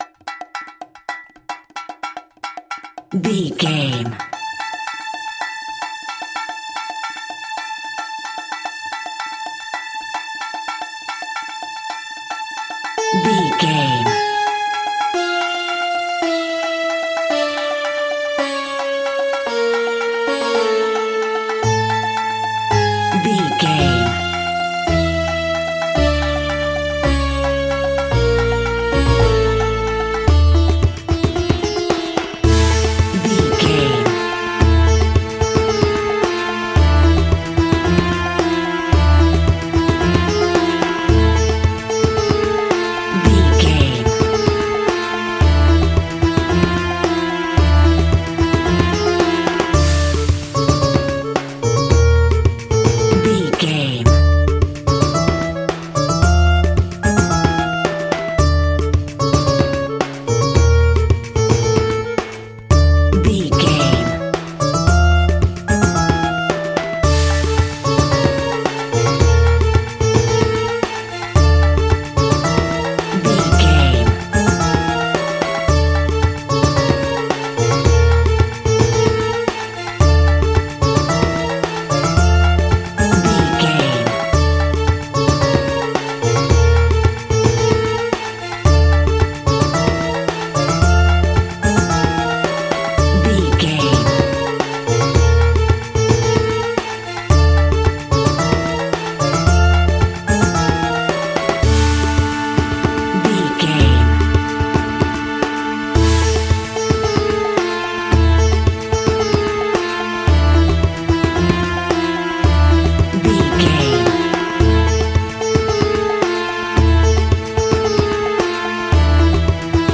Aeolian/Minor
sitar
bongos
sarod
tambura